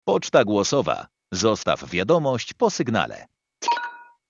Orange - Poczta głosowa Zostaw wiadomość (HD Voice).mp3